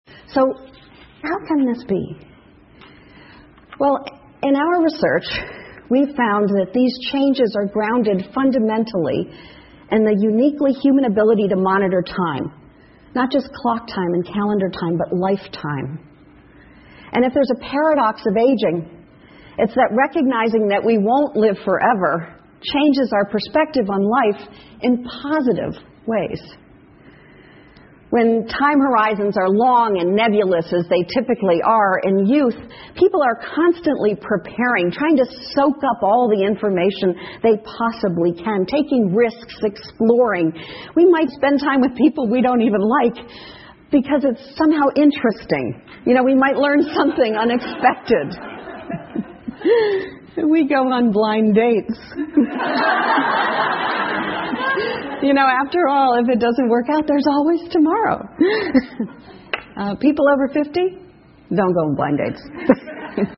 TED演讲:老年人更快乐() 听力文件下载—在线英语听力室